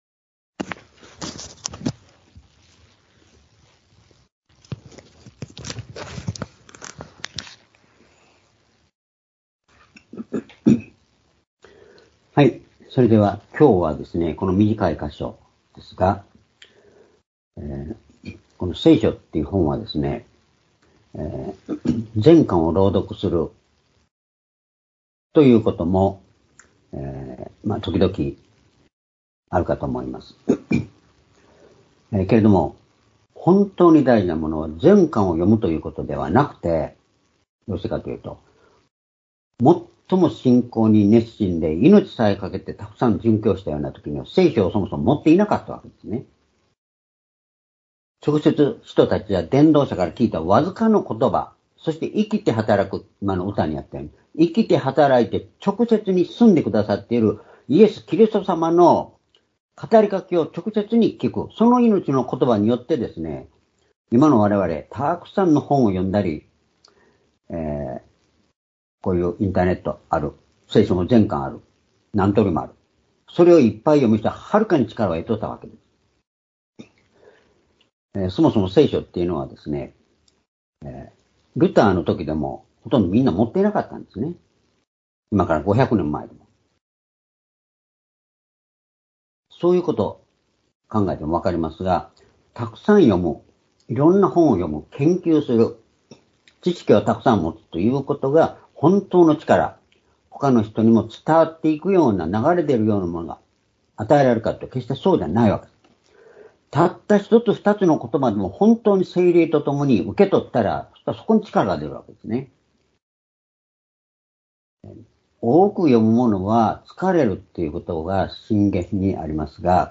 「真理は自由を与えるー真理、自由とはなにか」-ヨハネ8章３１節～３２節-２０２３年３月５日（日）主日礼拝
（主日・夕拝）礼拝日時 ２０２３年３月５日（日）主日礼拝 聖書講話箇所 「真理は自由を与えるー真理、自由とはなにか」 ヨハネ8章３１節～３２節 ※視聴できない場合は をクリックしてください。